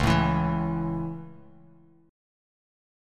C#m#5 chord